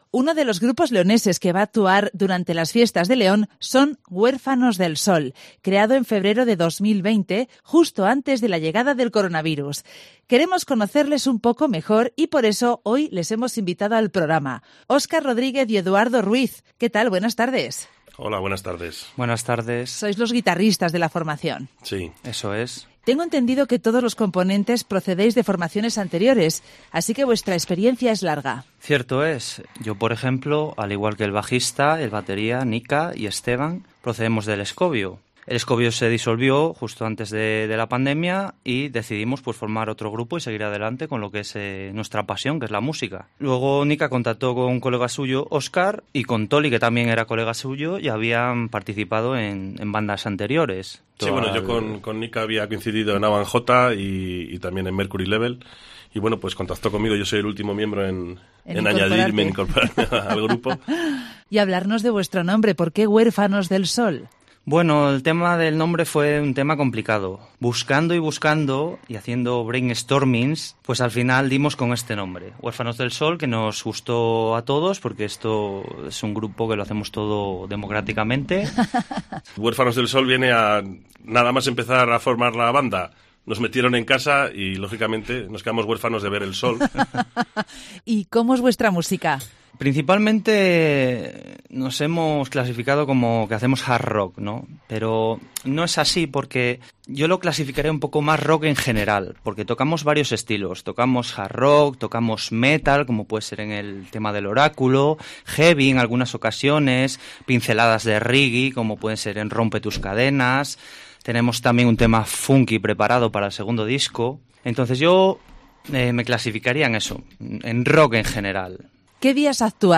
El grupo leonés "Huérfanos del Sol" visita el estudio de Cope León para presentar sus dos actuaciones durante las fiestas de San Juan y San Pedro 2022.